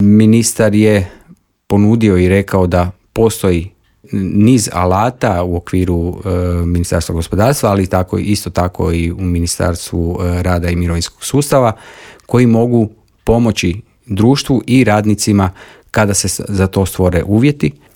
Za trenutno stanje u Varteksu nisu krivi radnici, već loše upravljanje, poručio je u Intervjuu Media servisa